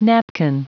Prononciation du mot napkin en anglais (fichier audio)
Prononciation du mot : napkin